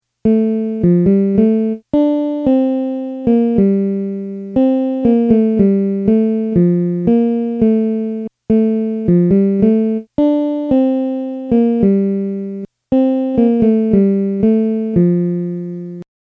dormi-mia-bella-melody.mp3